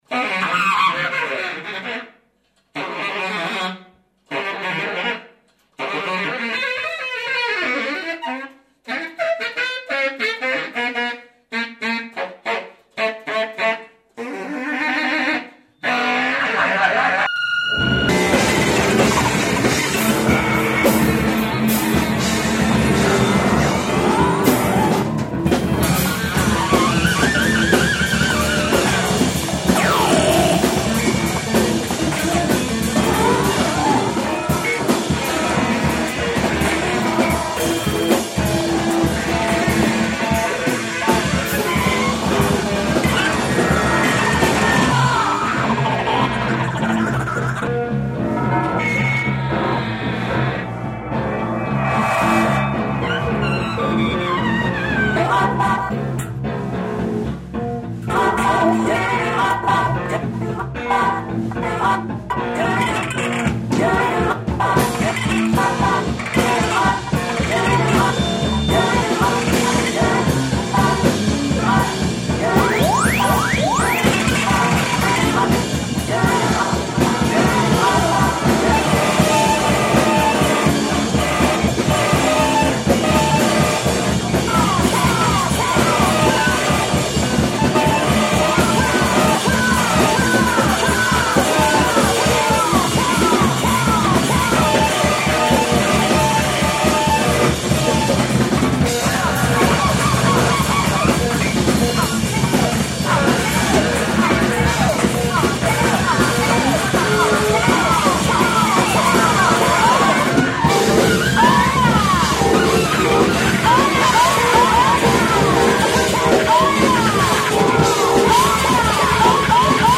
the intersection of free jazz, noize and hardcore.
sample treatments
guitar
drums
saxophon